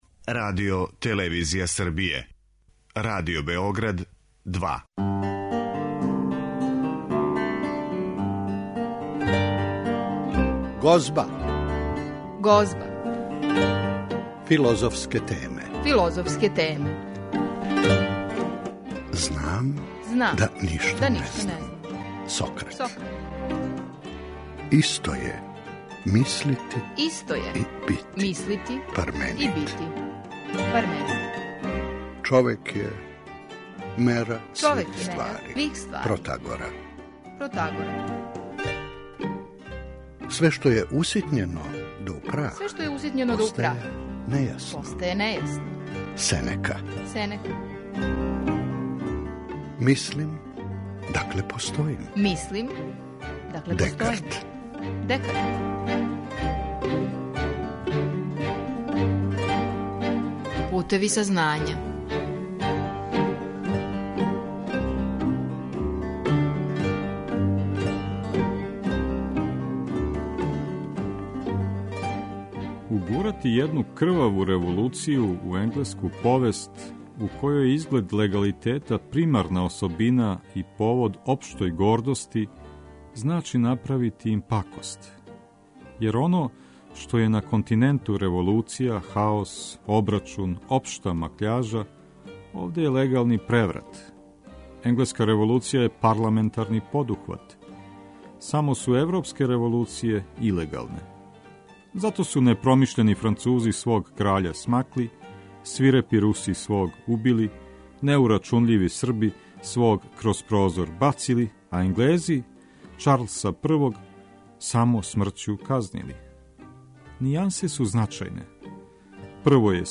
О револуцији и просветитељству разговарамо